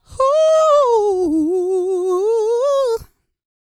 E-GOSPEL 134.wav